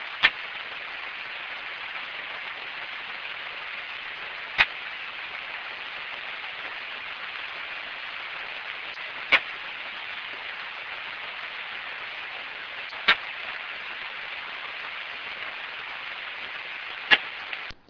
enregistrement nocturne du chant de la sauterelle ponctuée Leptophyes punctatissima (Pettersson D200 réglé sur 30 kHz - 06/09/2008 - Hamois, Belgique)